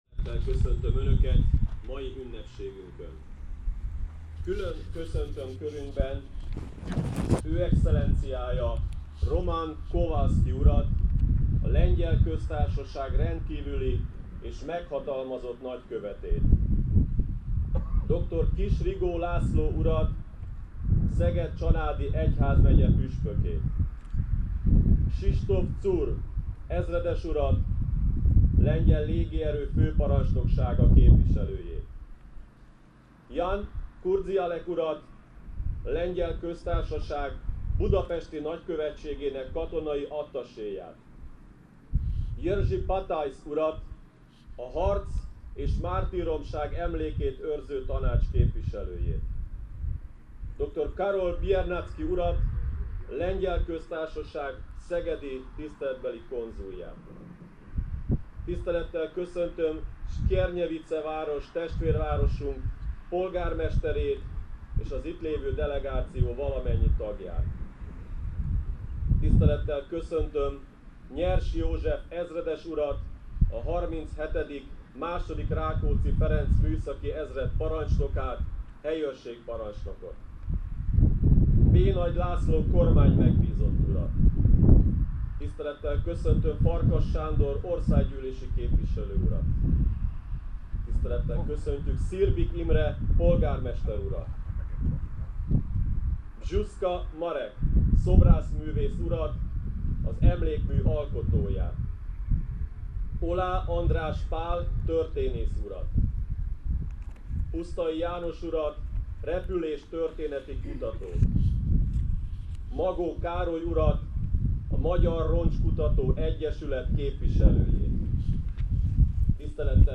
Az ünnepség képes-hangos eseményei alább tekinthetők meg:
Szirbik Imre polgármester beszéde.
A nagykövet beszéde.
A cudarul szeles, borongós időben az ünnepség zavartalanul folytatódott.